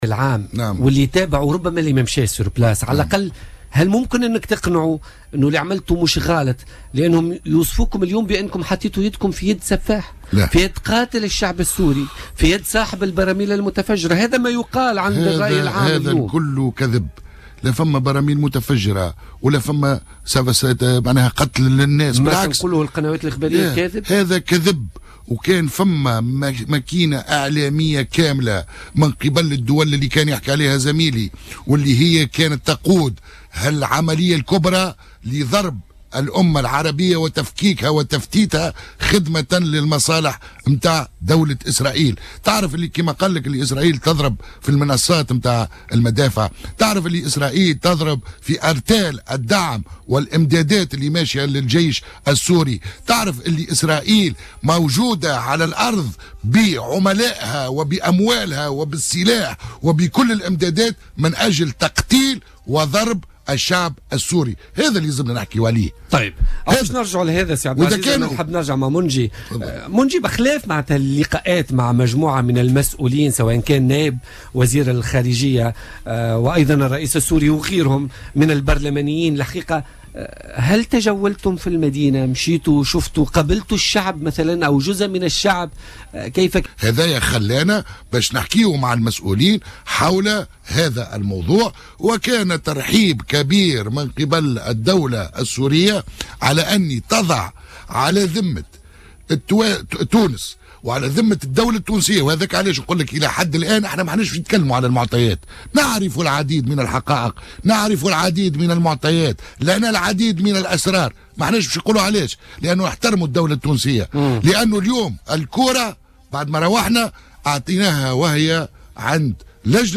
وقال ضيف "بوليتيكا" الذي كان ضمن الوفد البرلماني الذي زار مؤخرا سوريا، إن هذه الروايات مجرد كذبة تم استخدامها من وسائل الإعلام بعينها يسعى محركوها إلى اسقاط النظام السوري.